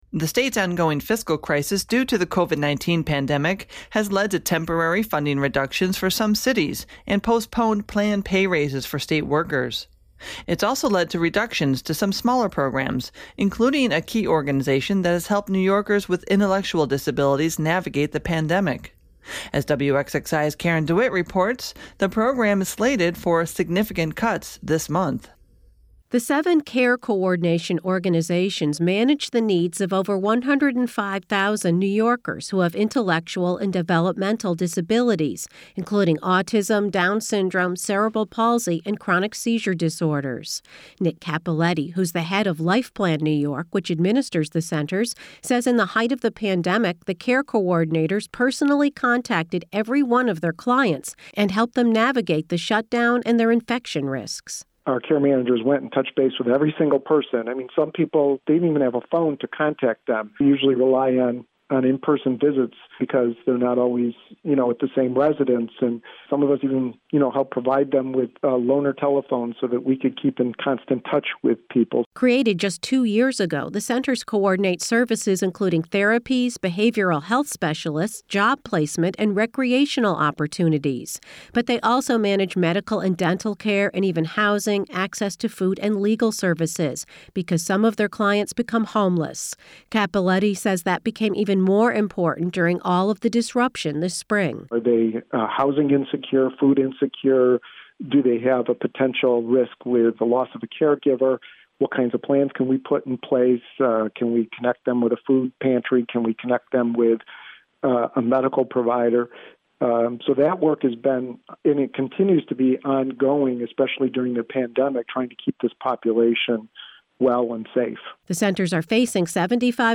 WXXI News